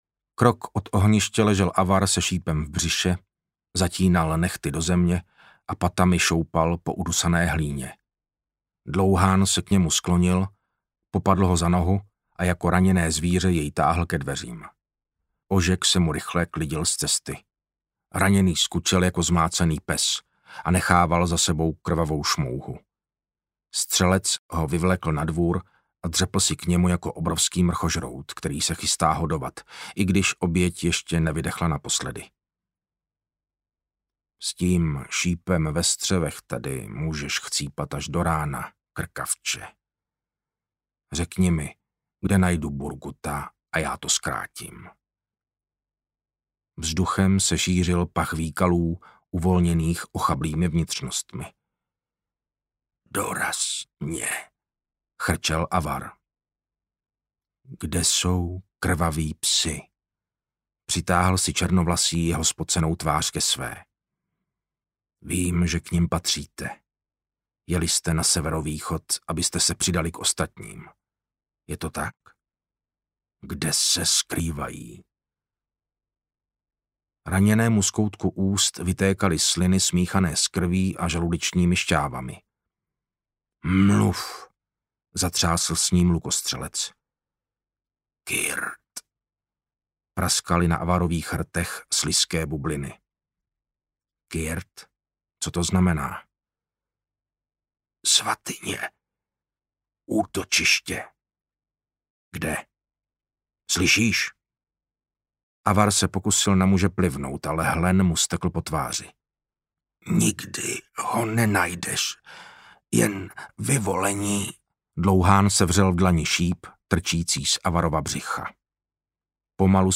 Vládce vlků audiokniha
Ukázka z knihy
• InterpretMartin Finger
vladce-vlku-audiokniha